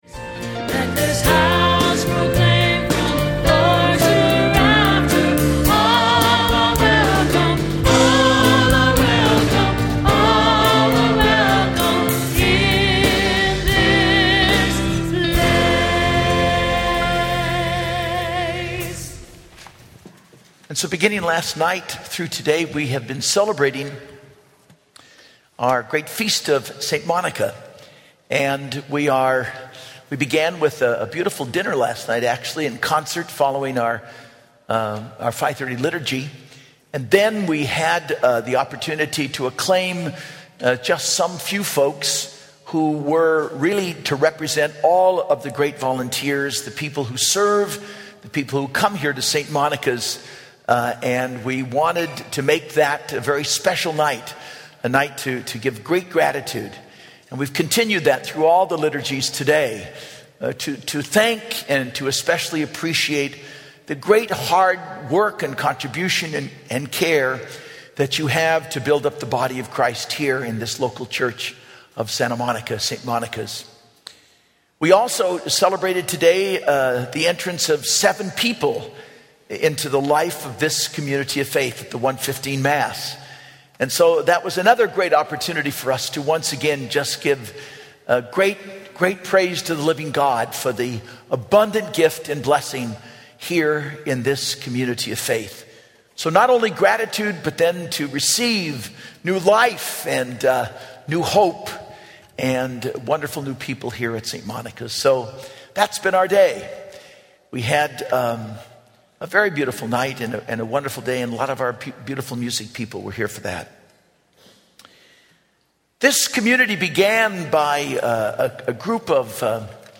Homily - 8/26/12 - Feast of St. Monica